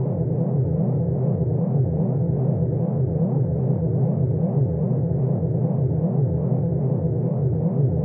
sci-fi_forcefield_hum_loop_09.wav